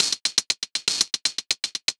Index of /musicradar/ultimate-hihat-samples/120bpm
UHH_ElectroHatC_120-03.wav